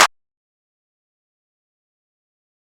Dro Clap (1).wav